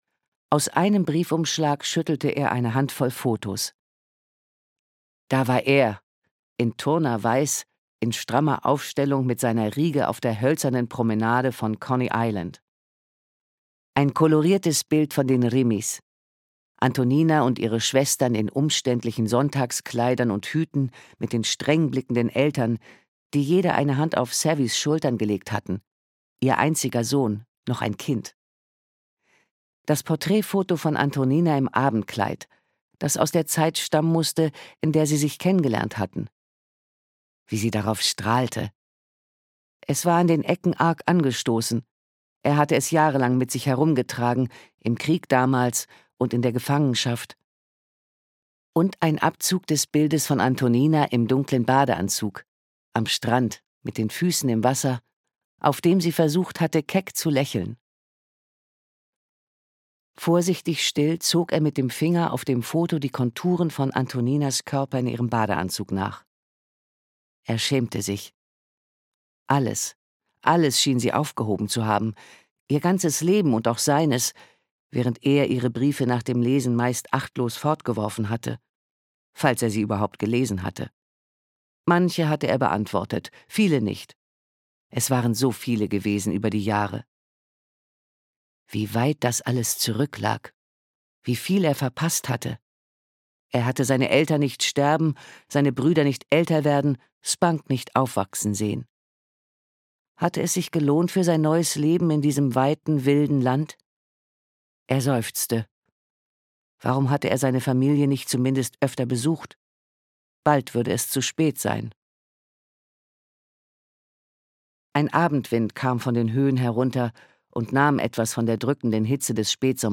Mit der Flut - Agnes Krup - Hörbuch